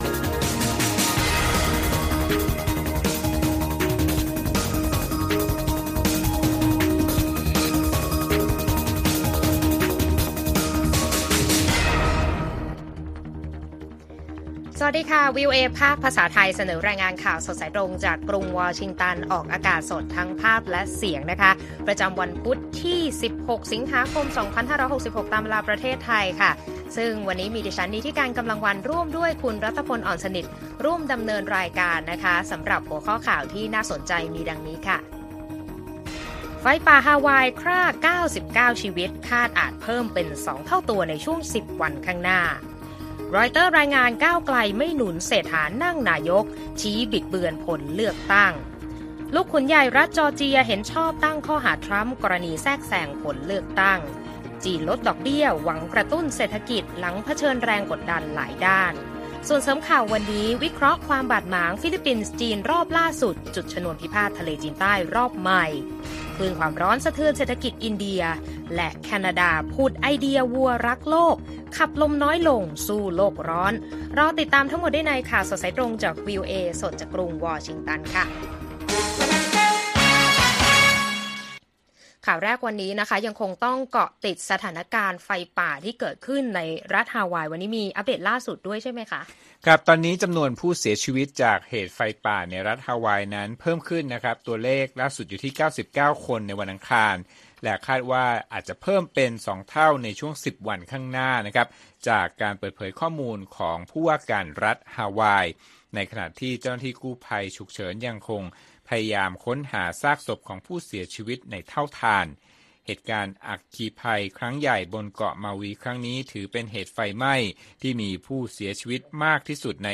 ข่าวสดสายตรงจากวีโอเอไทย 6:30 – 7:00 น. วันที่ 16 ส.ค. 2566